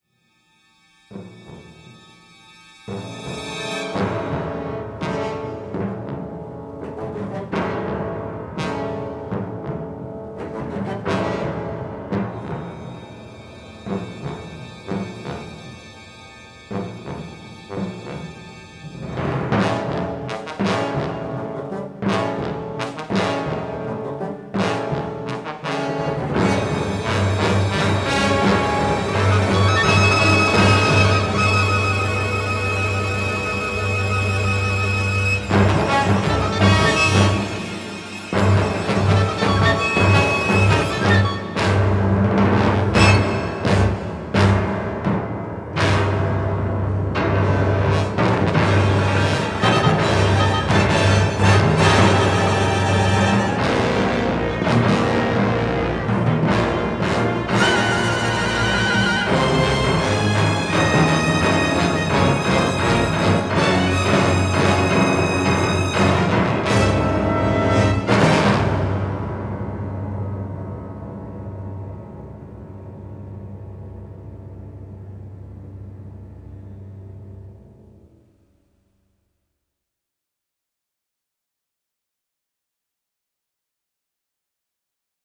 composed for full orchestra.